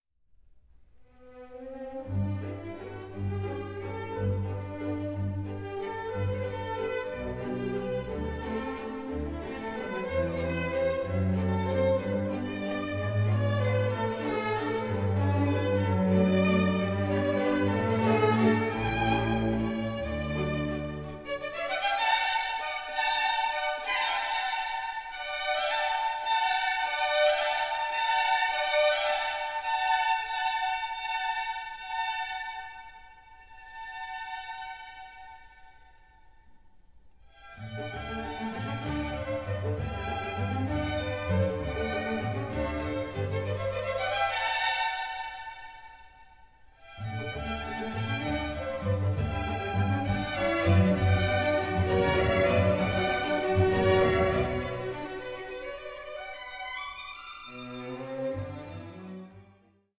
12) Ragtime for eleven instruments (1918) 4:45